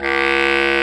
Index of /90_sSampleCDs/Roland L-CDX-03 Disk 1/WND_Lo Clarinets/WND_CB Clarinet